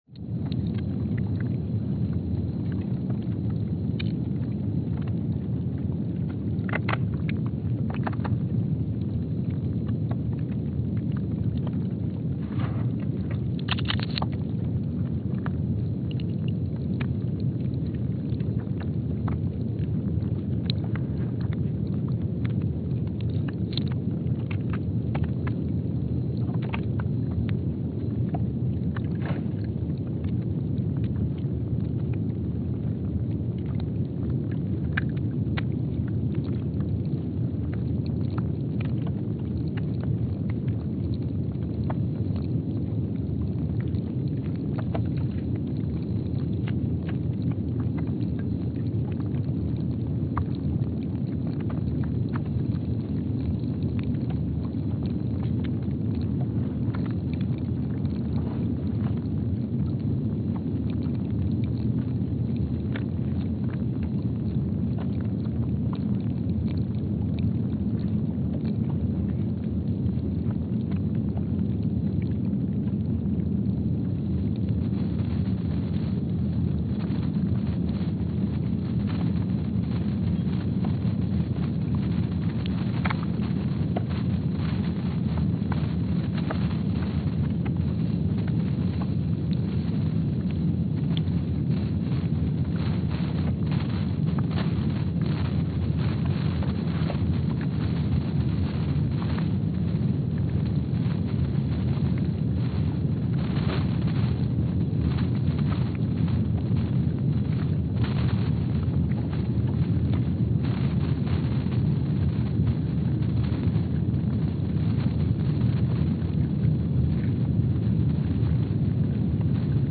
Palmer Station, Antarctica (seismic) archived on February 20, 2025
Sensor : STS-1VBB_w/E300
Speedup : ×500 (transposed up about 9 octaves)
Loop duration (audio) : 05:45 (stereo)
Gain correction : 25dB